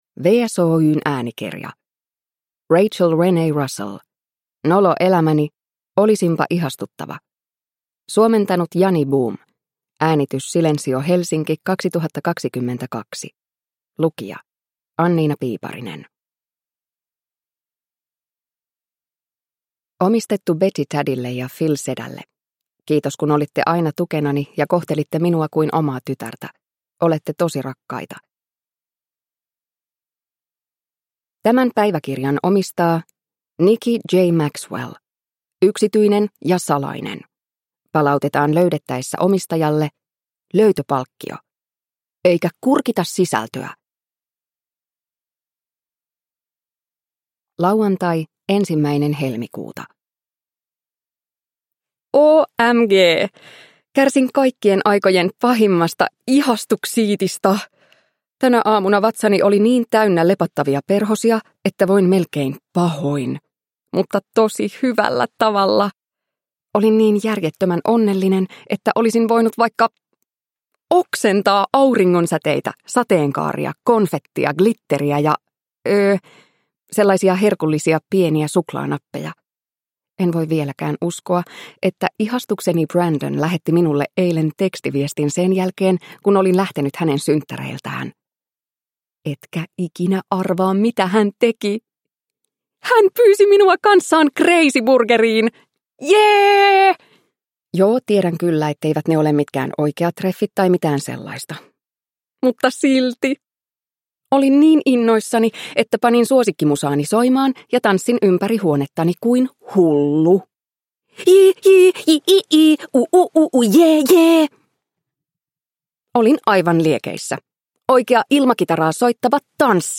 Nolo elämäni: Olisinpa ihastuttava – Ljudbok – Laddas ner